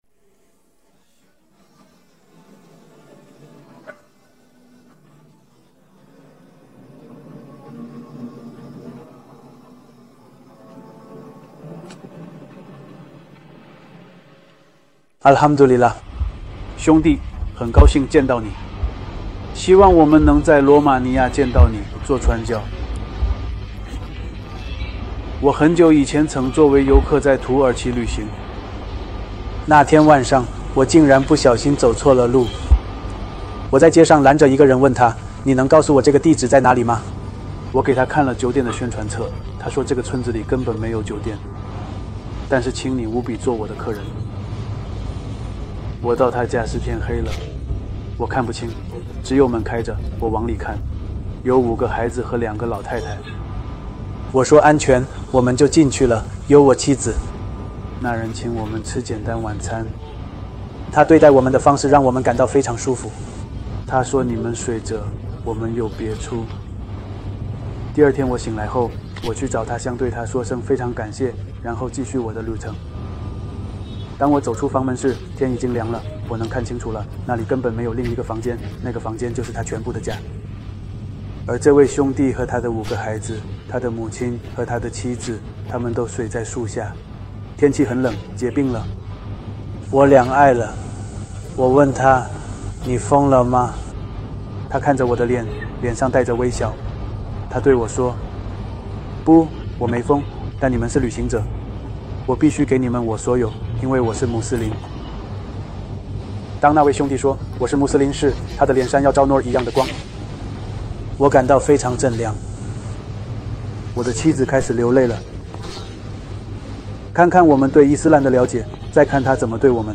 视频 新穆斯林故事 男性